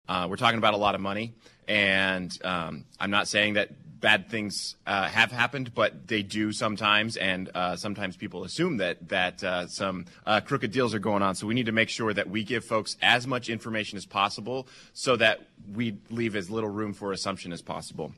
During this past weekend’s League of Women Voters Forum on KMAN, candidates were asked what the city’s role should be in spurring development of jobs that provide a livable wage.
Seven took part in the forum Saturday at the Manhattan Public Library, hosted by the League and co-sponsored by the local chapter of the American Association of University Women and the Manhattan Area Chamber of Commerce.